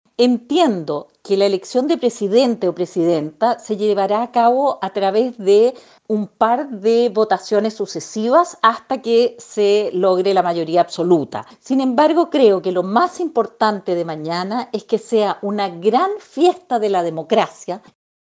Esto se hará a través de rondas de votación donde quienes postulen necesitarán mayoría absoluta de 78 votos, así lo aseguró la constituyente Patricia Politzer.